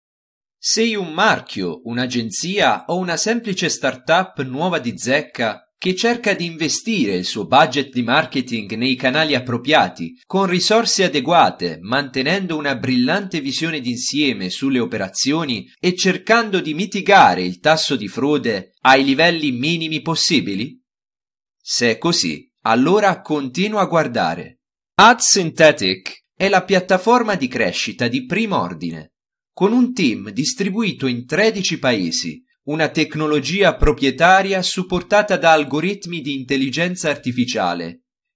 外籍英语配音